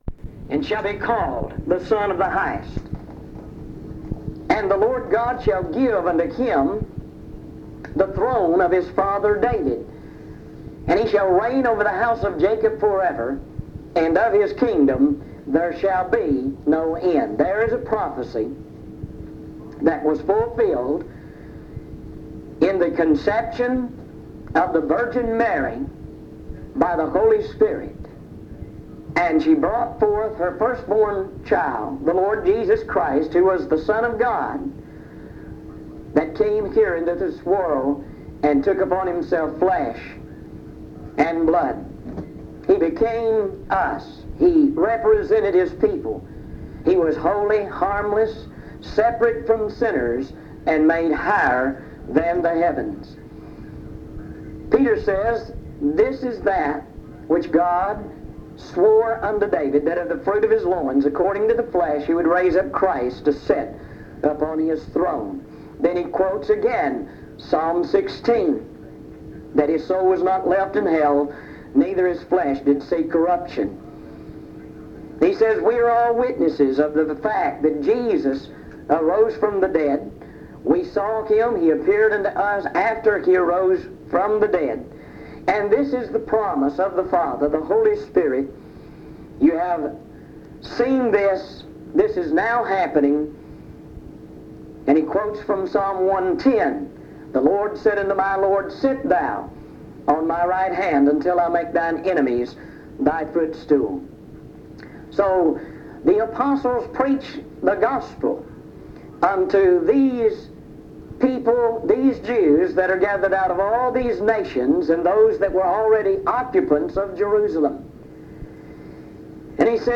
Acts 2; Recording from a broadcast